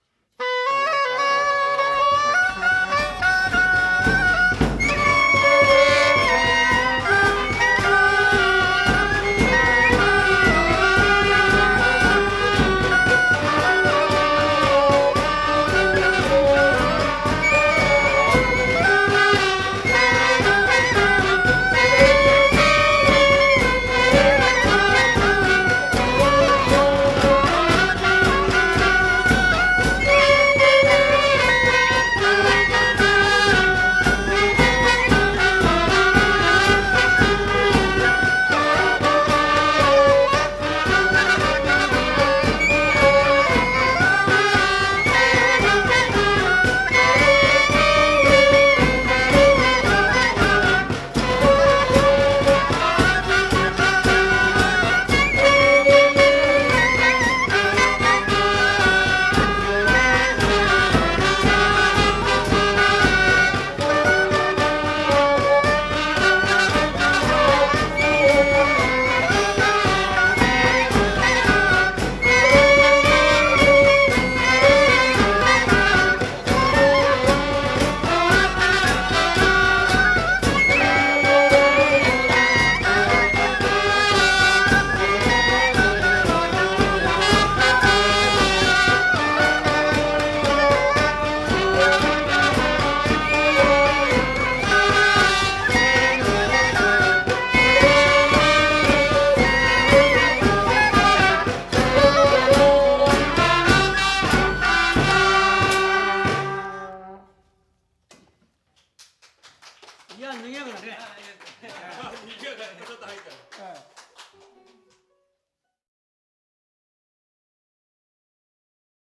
生活の強度が自由な音として吹き荒れる！